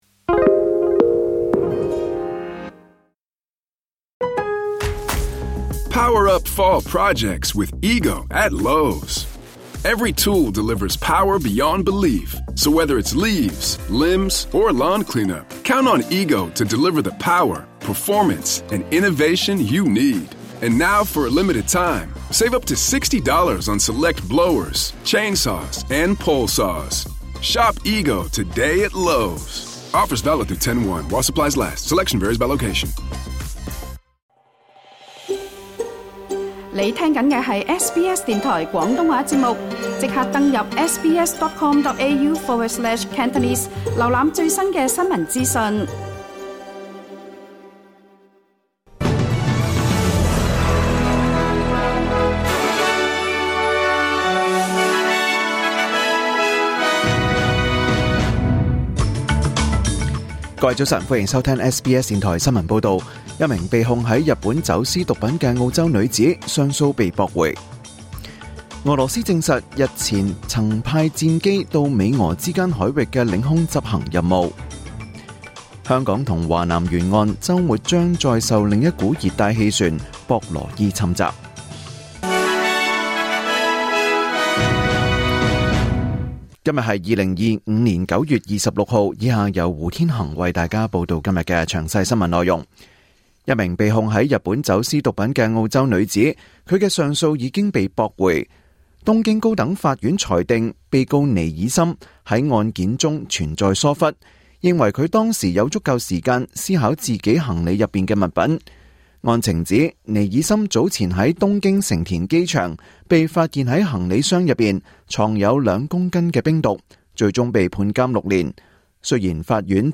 2025年9月26日 SBS 廣東話節目九點半新聞報道。